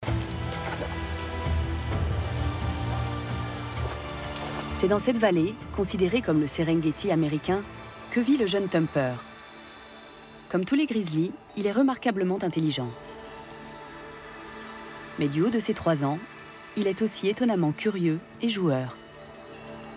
Narration : Touchez pas au grizzli